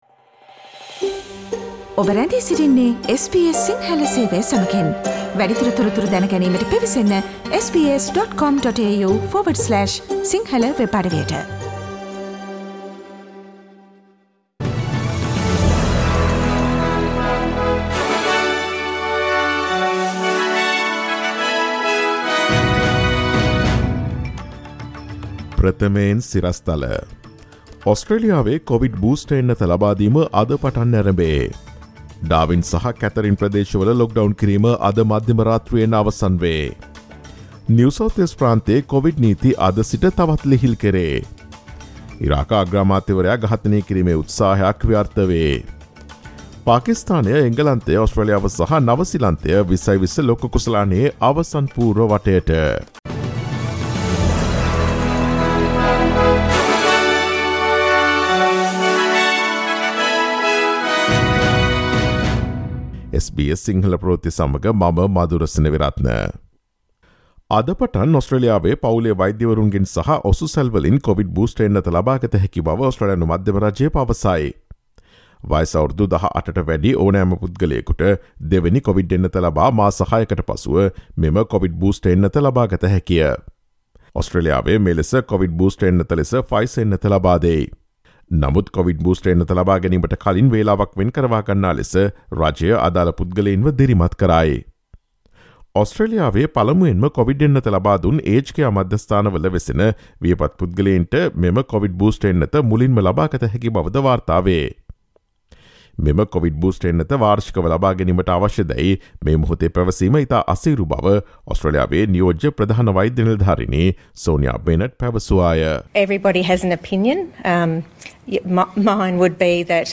ඔස්ට්‍රේලියාවේ නවතම පුවත් මෙන්ම විදෙස් පුවත් සහ ක්‍රීඩා පුවත් රැගත් SBS සිංහල සේවයේ 2021 නොවැම්බර් 08 වන දා සඳුදා වැඩසටහනේ ප්‍රවෘත්ති ප්‍රකාශයට සවන් දීමට ඉහත ඡායාරූපය මත ඇති speaker සලකුණ මත click කරන්න.